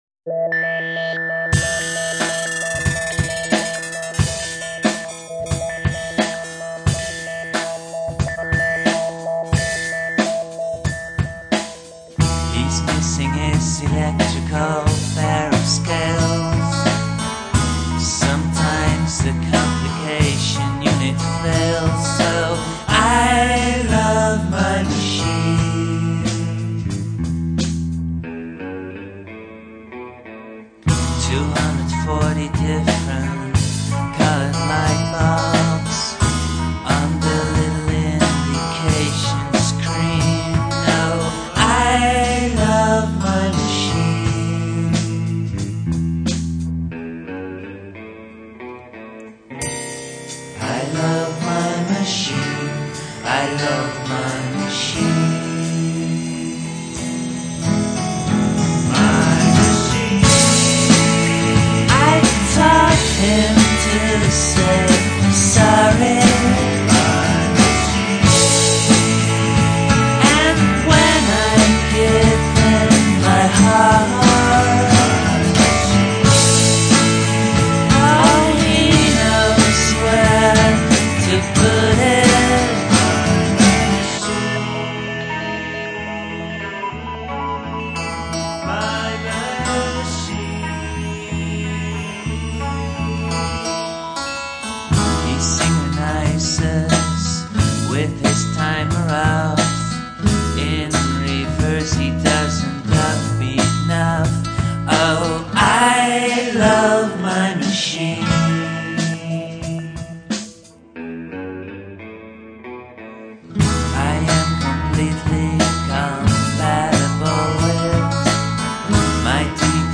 where: Studio Aluna , Amsterdam